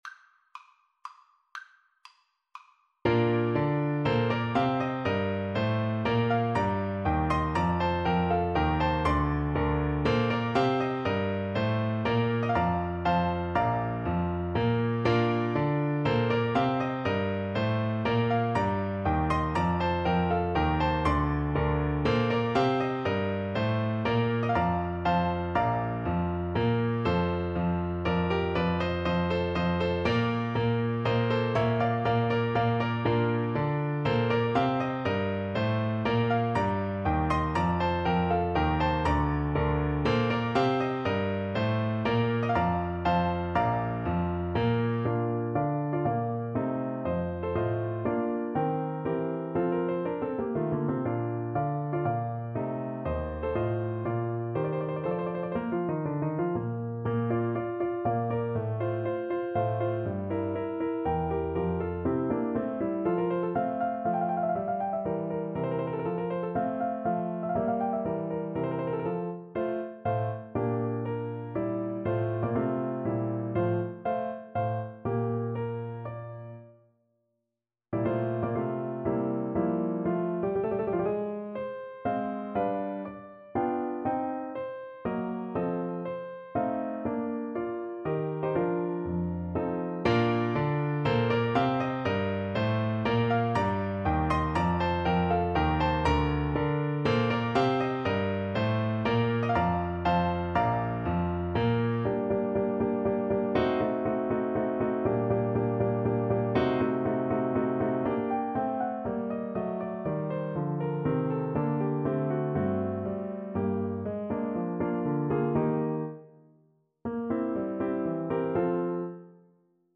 Play (or use space bar on your keyboard) Pause Music Playalong - Piano Accompaniment Playalong Band Accompaniment not yet available reset tempo print settings full screen
Bb major (Sounding Pitch) (View more Bb major Music for Bassoon )
3/4 (View more 3/4 Music)
=120 RONDO Tempo di Menuetto
Classical (View more Classical Bassoon Music)